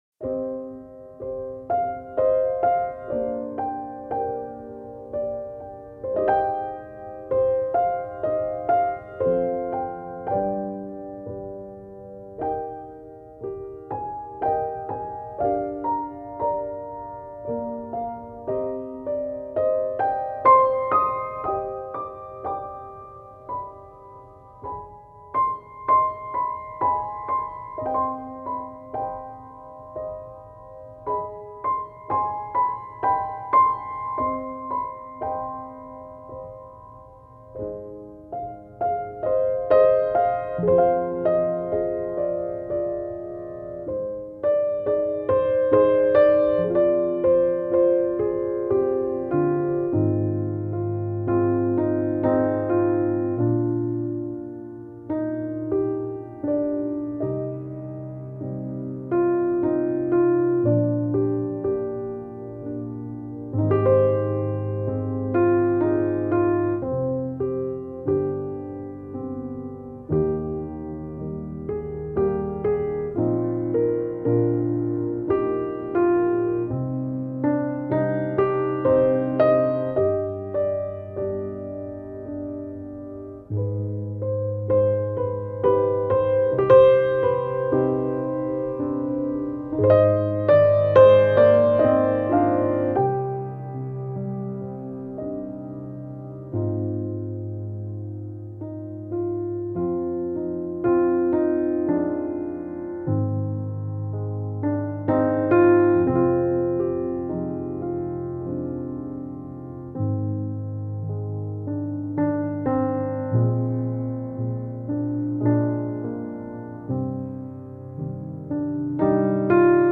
Sentimental solo piano theme pondering on life.